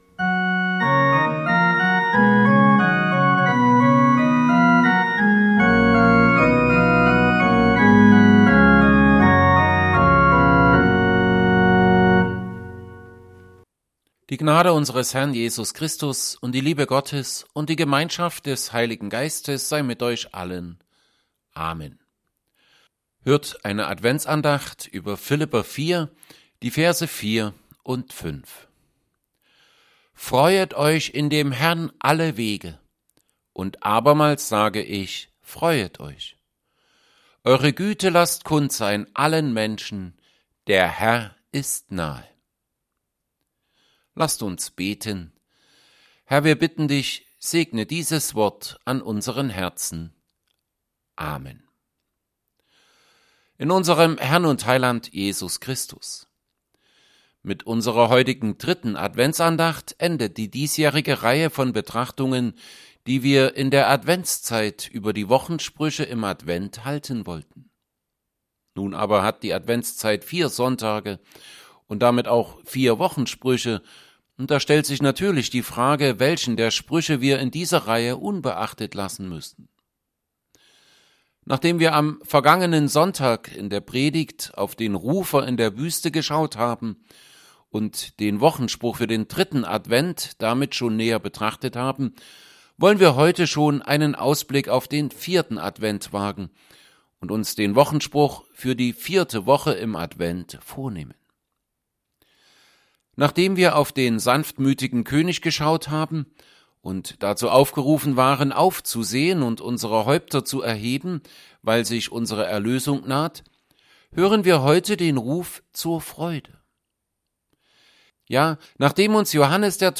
3. Adventsandacht 2021